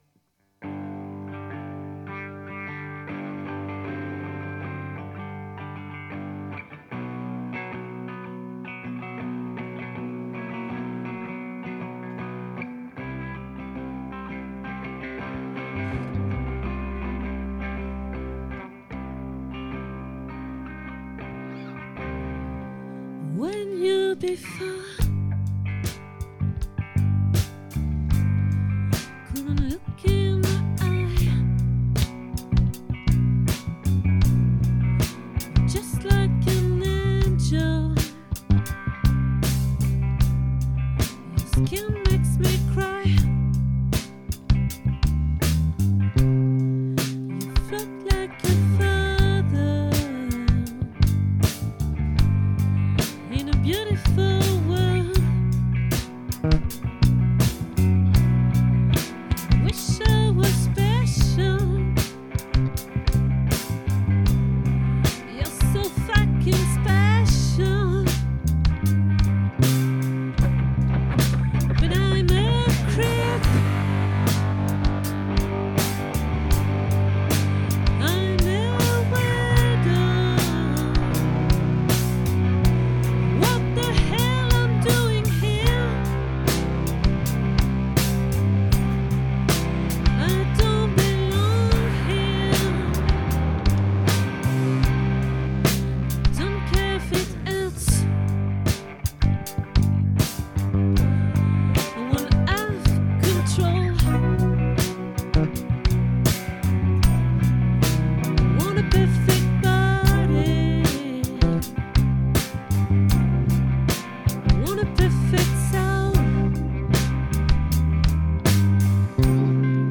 🏠 Accueil Repetitions Records_2025_12_15